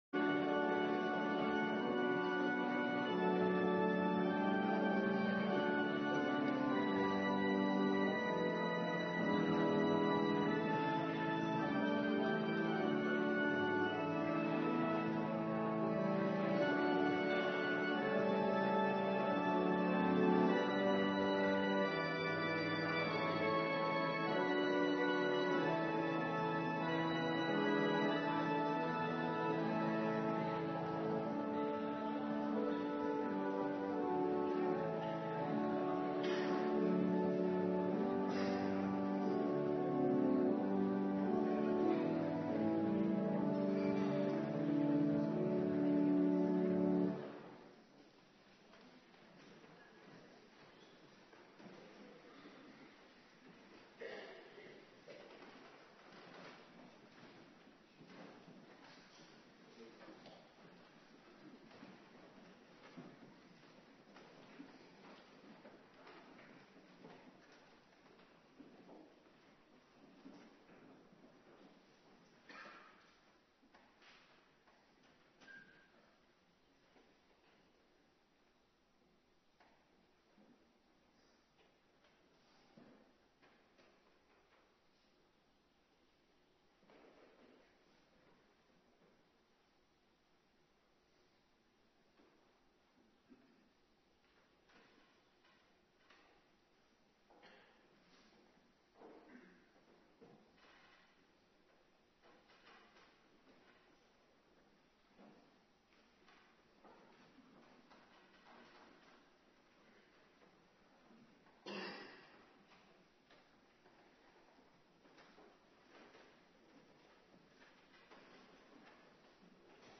Preken (tekstversie) - Geschriften - De roeping van de man | Hervormd Waarder